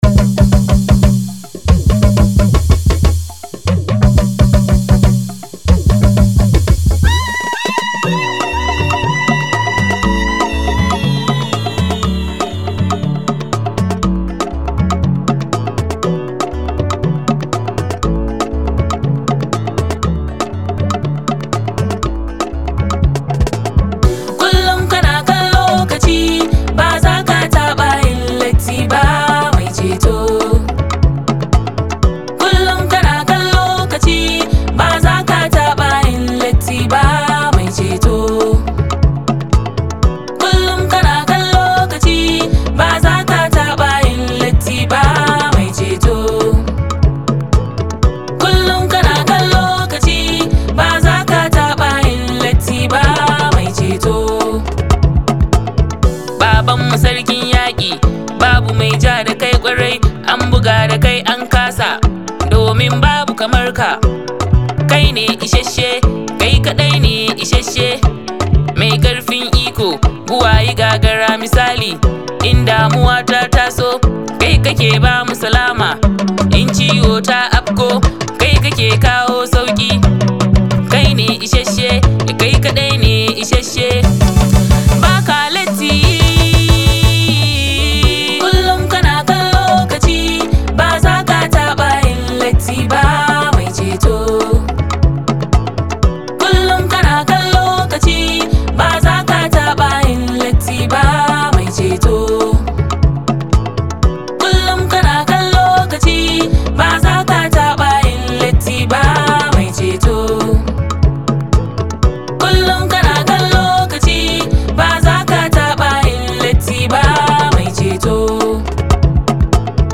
Naija Gospel Songs